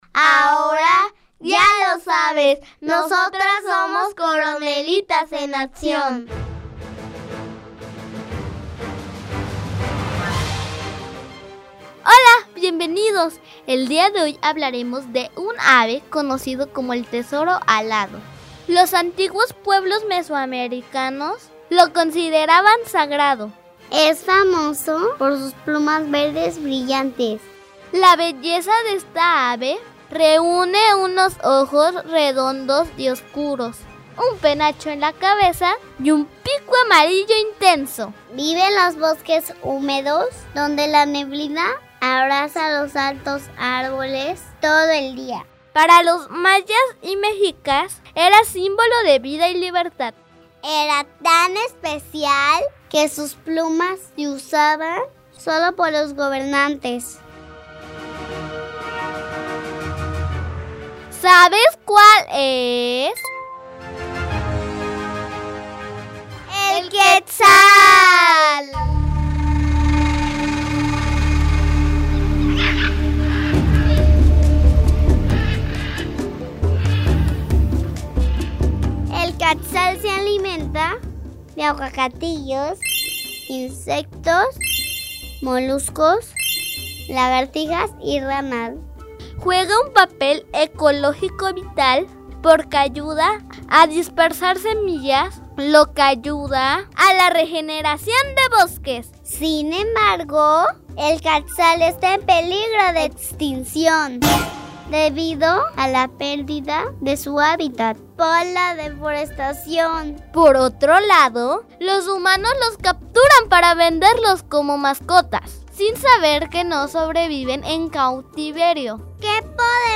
Son cápsulas radiofónicas que se encargan de hacer reflexionar acerca de los derechos de los animales, enfocándose en el conocimiento de especies en peligro de extinción o vulnerables, y haciendo referencia a la Declaración Universal de los Derechos de los Animales.